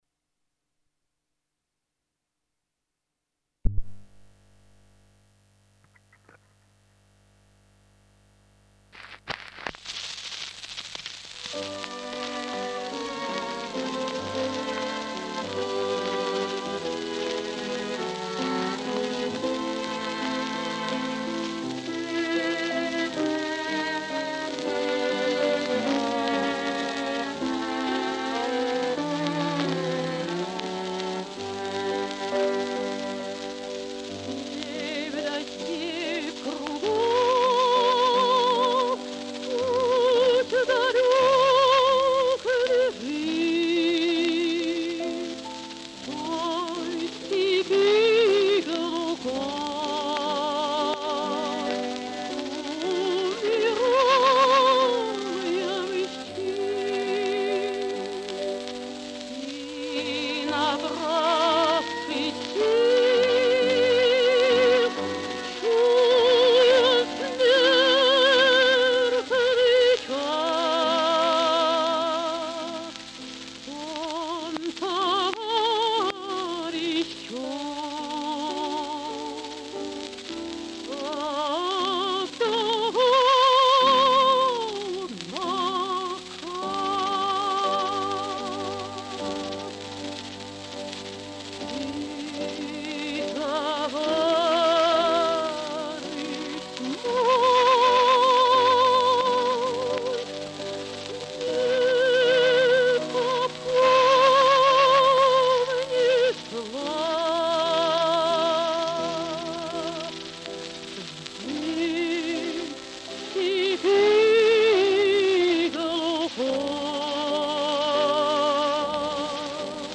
Надежда Андреевна Обухова-Степь да степь кругом (Апрелевский завод шеллак) 1946